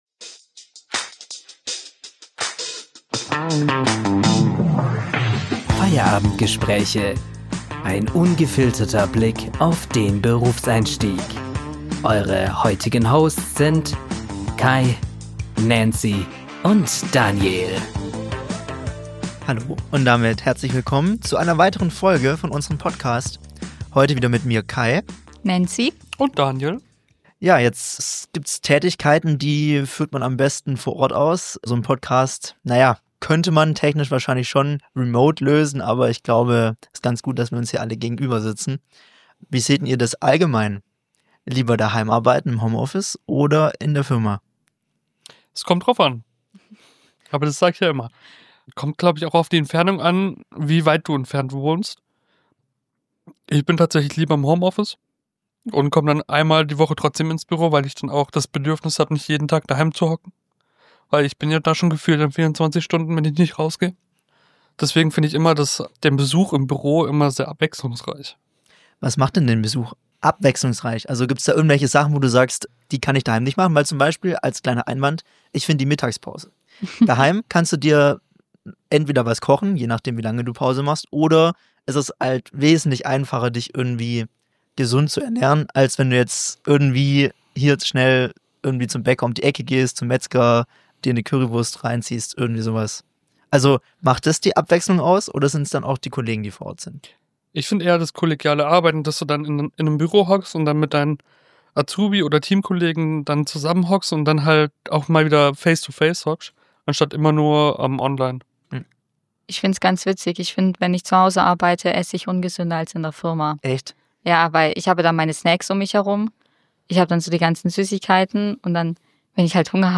Die Hosts teilen ihre ganz persönlichen Erfahrungen zwischen Produktivitätsboost und Jogginghosenkomfort und verraten, warum eine gute Mischung vielleicht die perfekte Lösung ist.